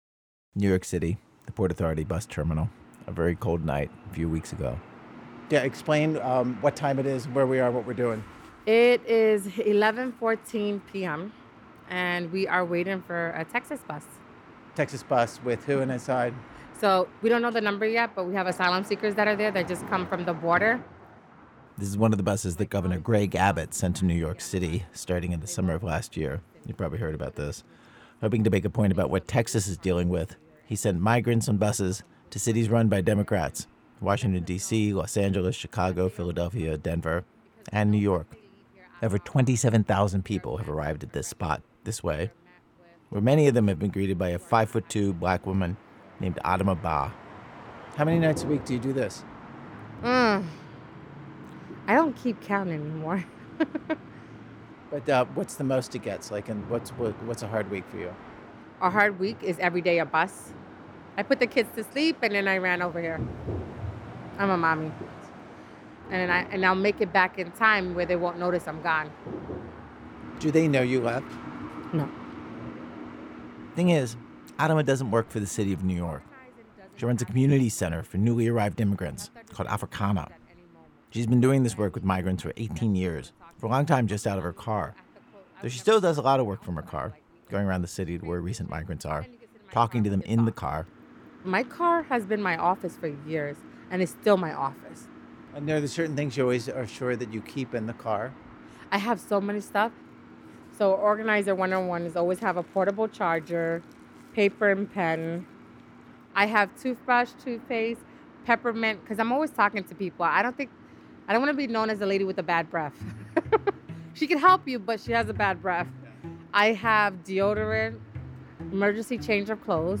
Note: The internet version of this episode contains un-beeped curse words.
In the middle of the night, host Ira Glass meets a woman on a mission at Port Authority bus station.